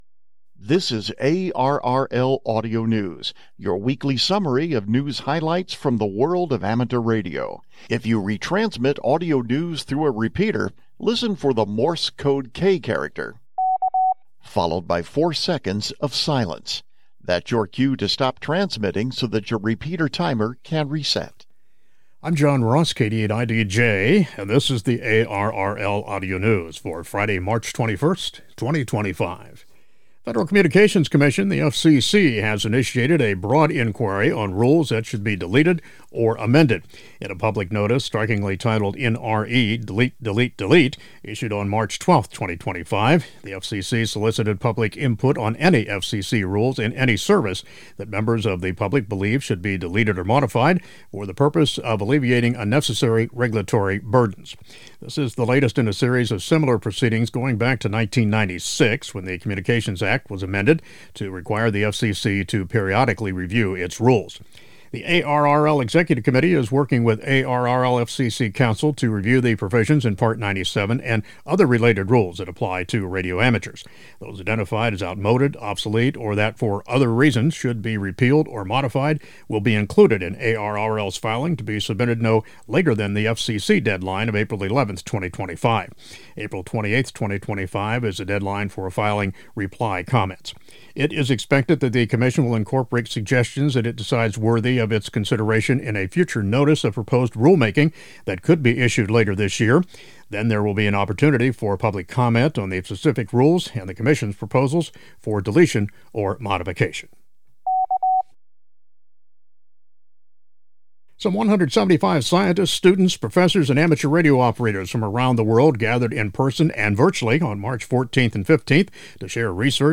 For those airing ARRL Audio News on repeaters, listen for the letter "K" sent in Morse code every three minutes or so; that signals a four second pause in the audio.